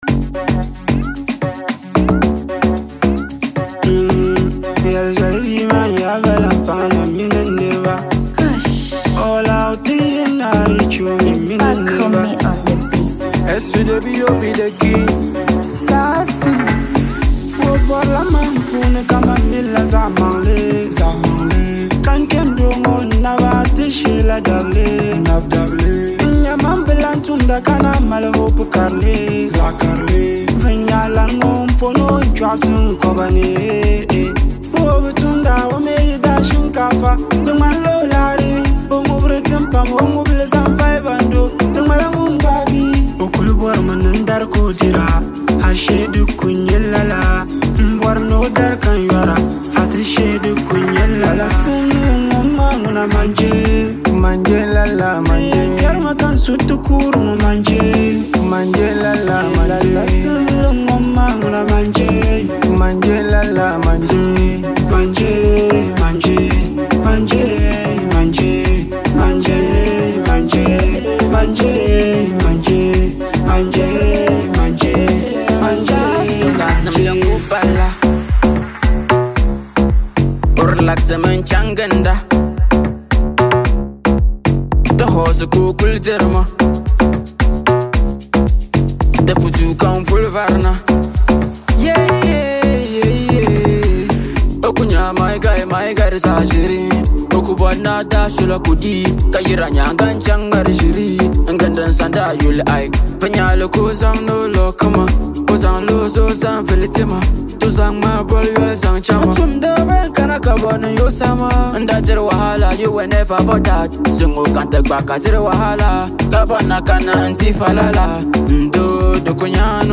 GHANA MUSIC
This energetic jam